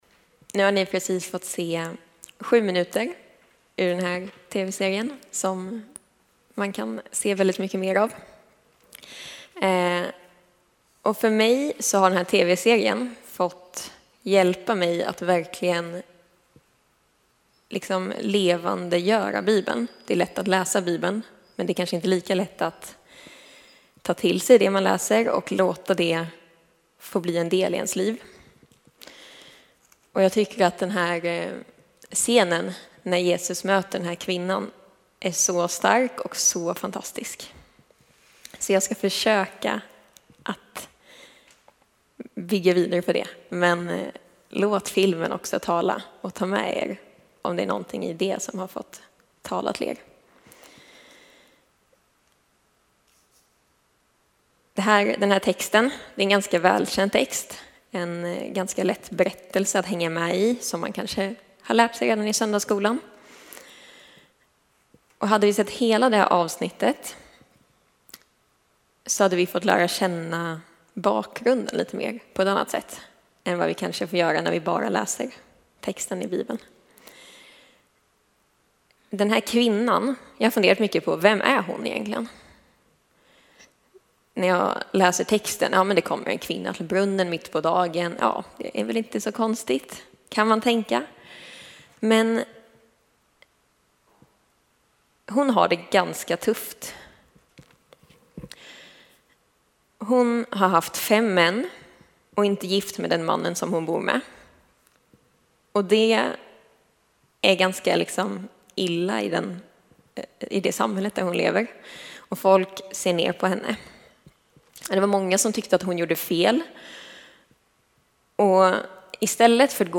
Innan predikan visades ett klipp från Tv-serien ”The Chosen”.